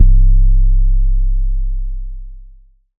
MZ 808 [Oz].wav